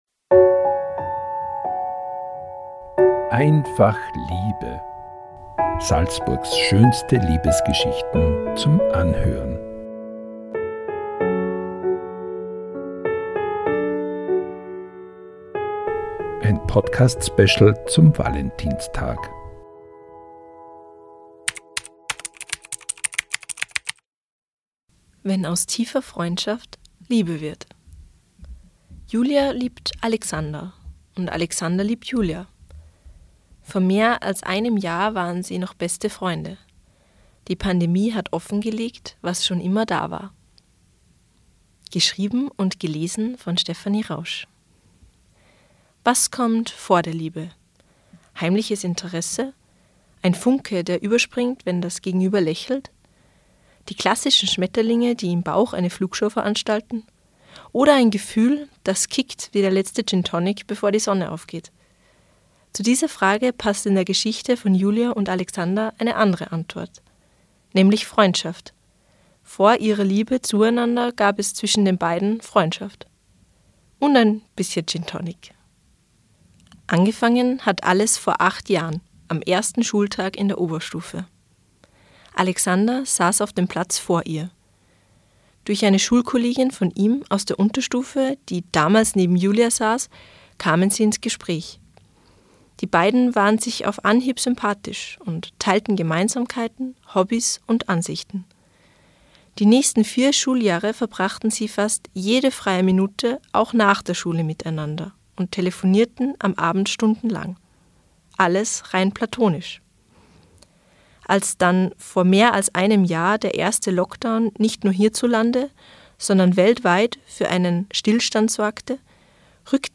Pünktlich zum Valentinstag lesen SN-Redakteurinnen und Redakteure Salzburgs schönste Liebesgeschichten ein.